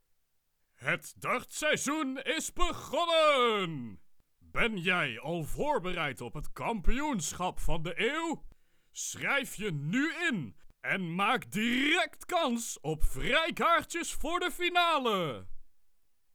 Vanaf het komende fragment, zijn de opnames thuis ingesproken.
-Microfoon = Neumann TLM103 + Shockmount + popfilter
In het onderstaande fragment heb ik een (fictieve) reclame opgenomen voor een aankomende dartcompetitie. Daarbij vond ik het belangrijk om het bombastische, het feestelijke en de urgentie van de actie duidelijk over te brengen.
dartseizoen reclame.wav